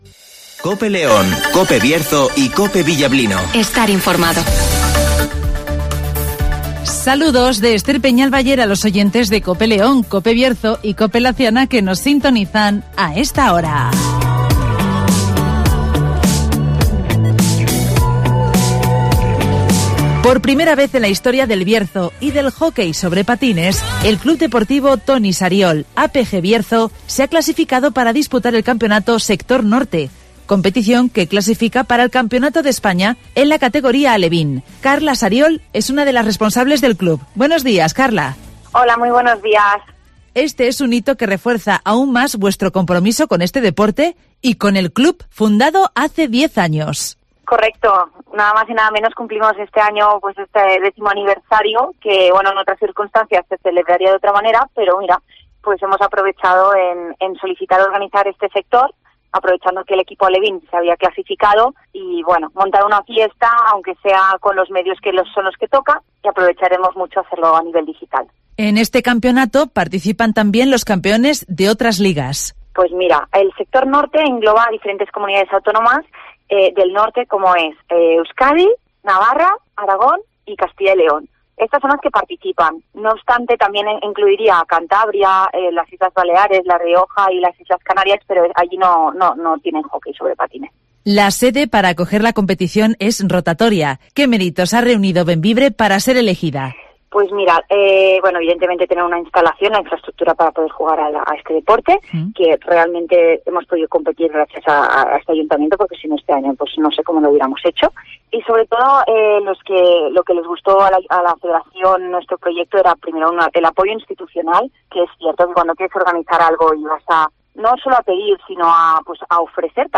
El Bierzo es la sede este fin de semana del campeonato de hockey patines en las categorías alevín y juvenil (Entrevista